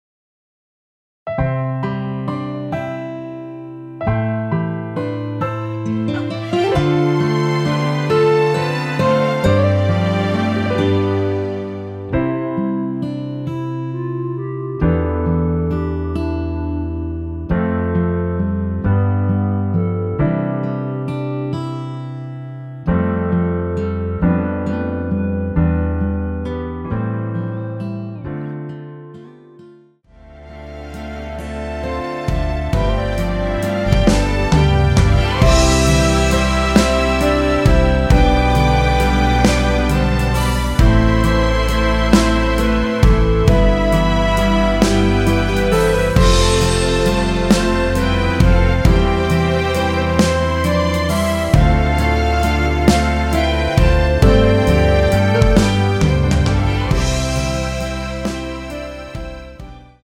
원키 멜로디 포함된 MR입니다.
앞부분30초, 뒷부분30초씩 편집해서 올려 드리고 있습니다.
중간에 음이 끈어지고 다시 나오는 이유는